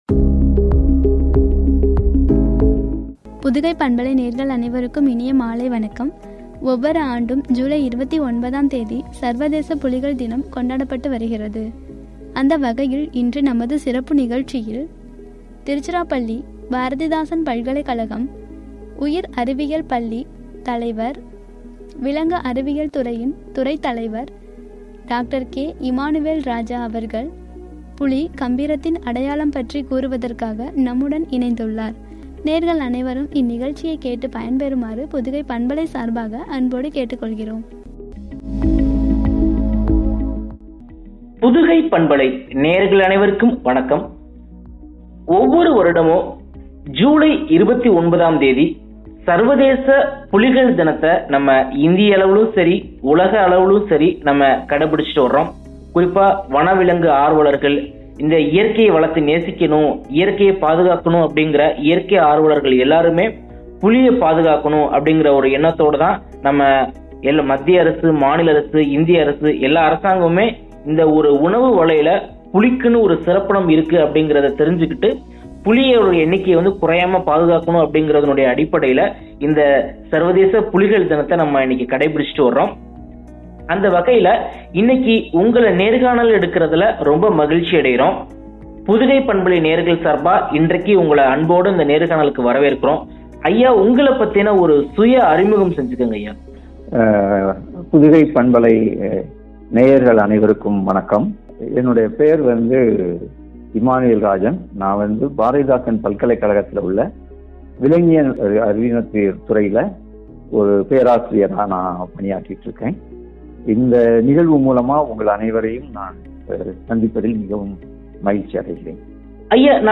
வழங்கிய உரையாடல்